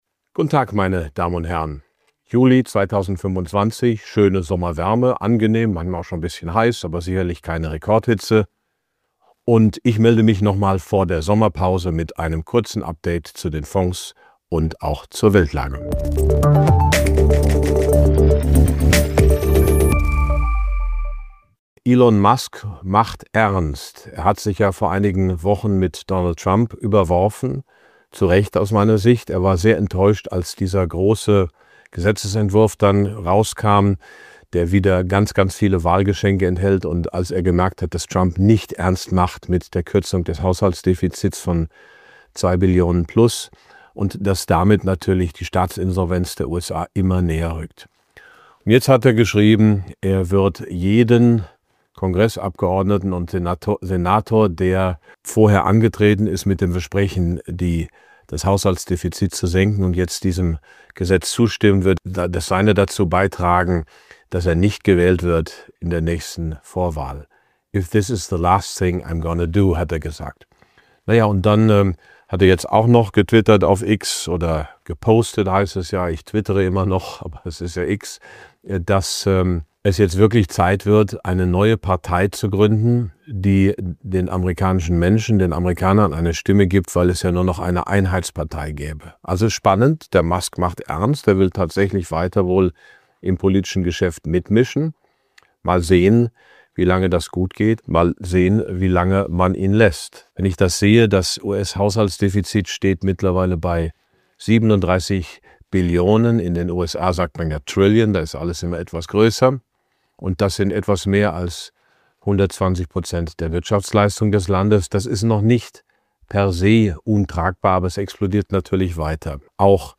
In diesem Kommentar beleuchtet Prof. Dr. Max Otte die Hintergründe der politischen Spannungen um das US-Defizit und die Auswirkungen auf die Weltwirtschaft. Erfahren Sie mehr über die strategische Bedeutung von Gold und Silber – eine langfristige Investmentperspektive in unsicheren Zeiten.